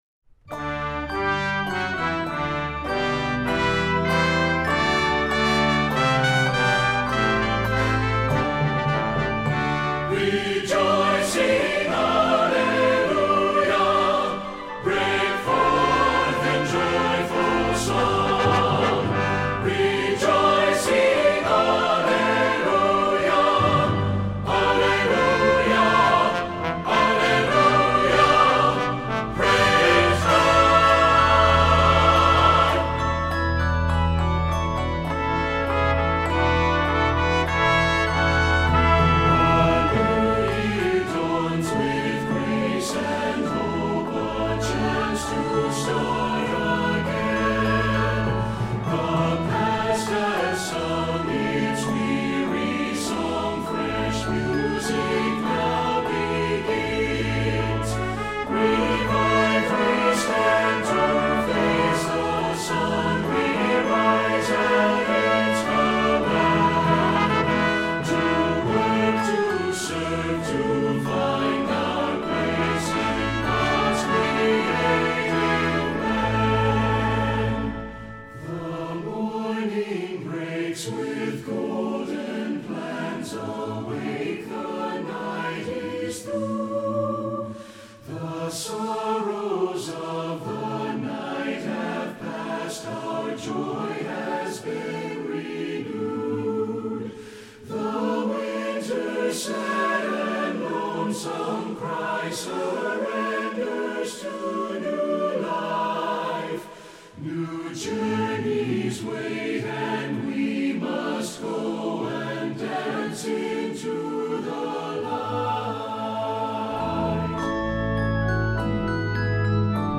Voicing: SATB and Congregation